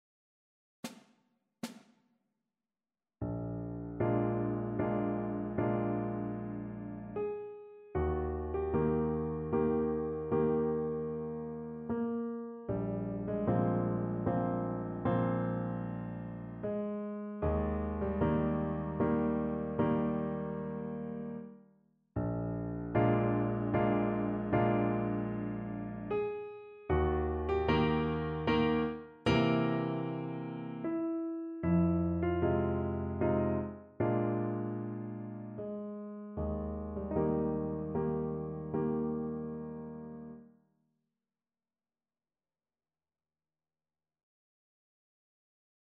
Symulacja akompaniamentu